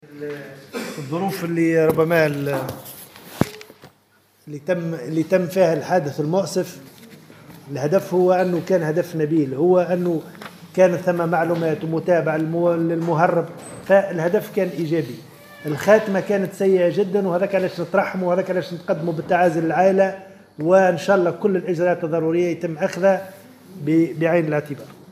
قال وزير المالية في تصريح لمراسل الجوهرة "اف ام" اليوم الخميس 1 نوفمبر 2018 إن الهدف من عملية سيدي حسين